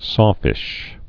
(sôfĭsh)